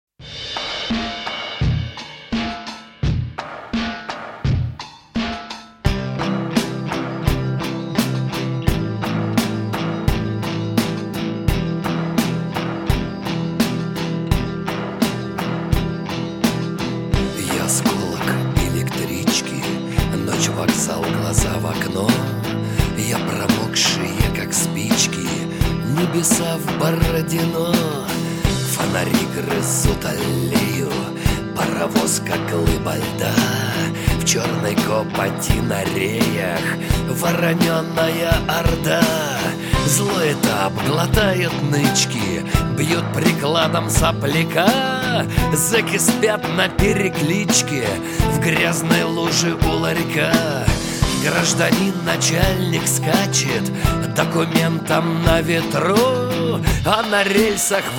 Рок
бескомпромиссные, жёсткие, ироничные, динамичные